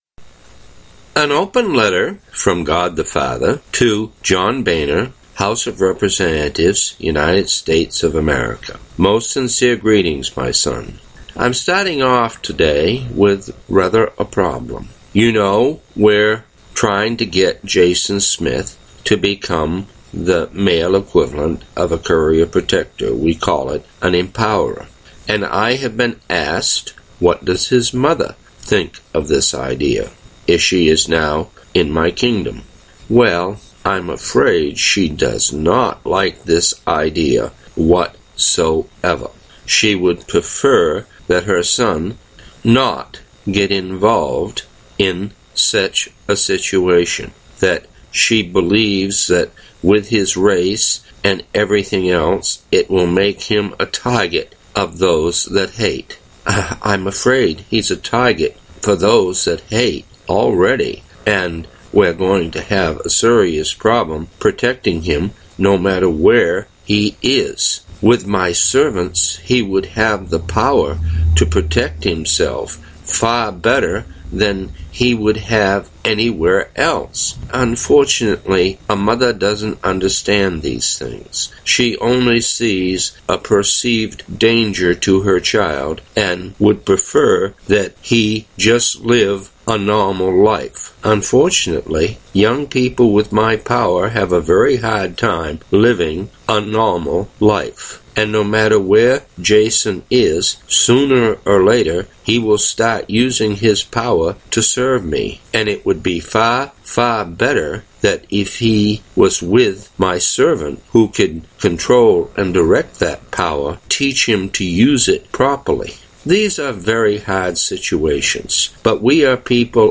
An Open Letter From God The Father